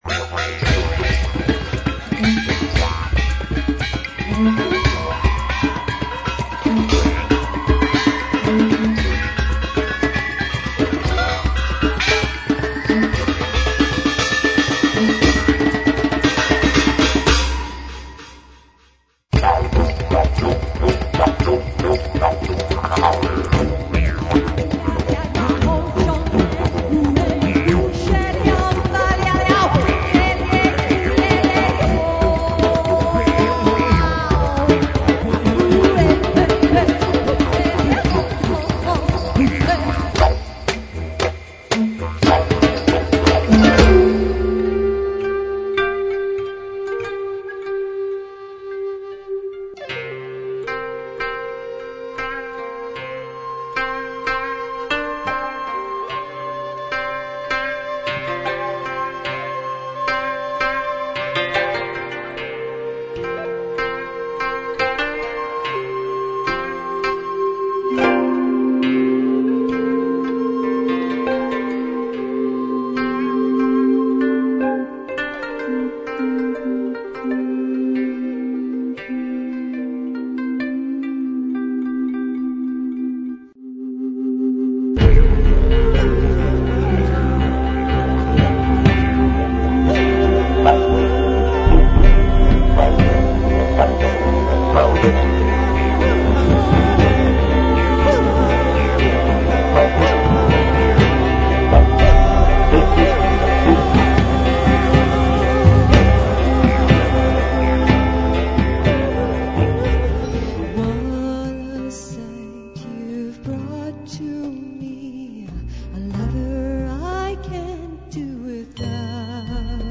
with Japanese koto and Native American Flute